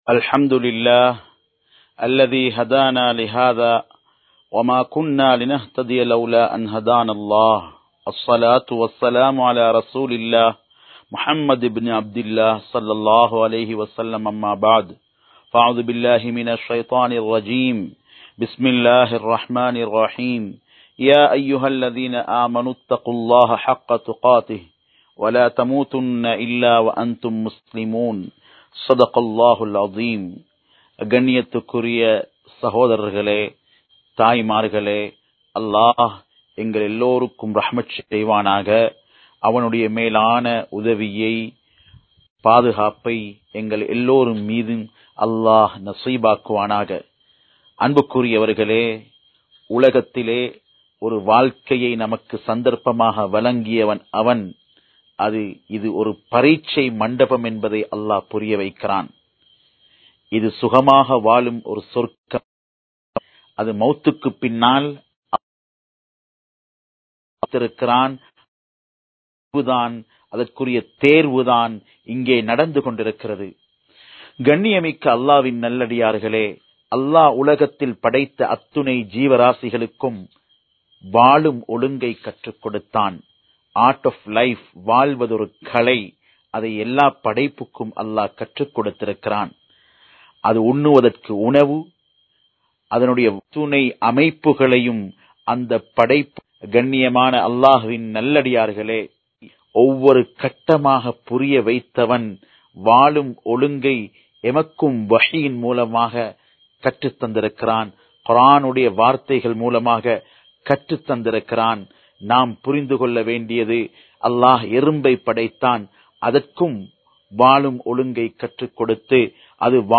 Islam Virumbum Veettu Soolal (இஸ்லாம் விரும்பும் வீட்டு சூழல்) | Audio Bayans | All Ceylon Muslim Youth Community | Addalaichenai
Live Stream